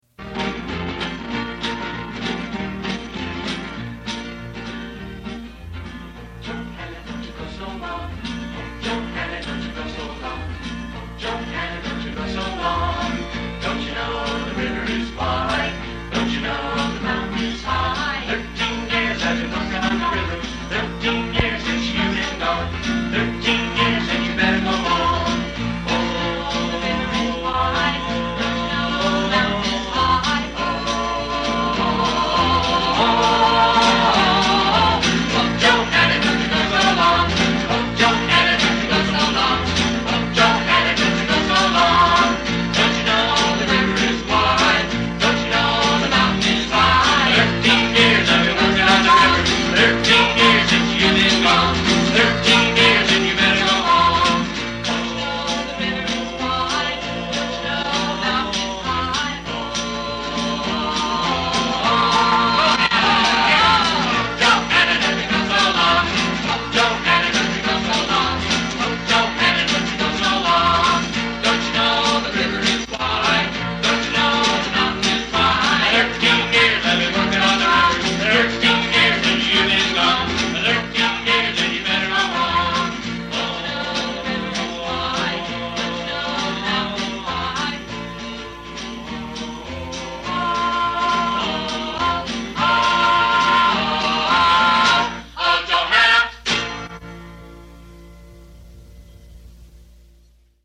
Recorded live when we were 15-17 years old